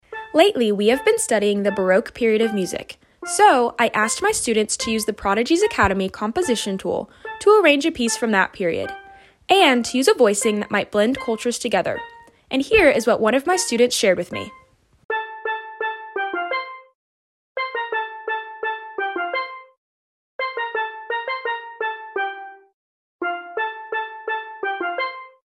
Vivaldi’s Four Seasons, Steel Drums, sound effects free download